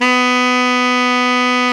SAX TENORM0B.wav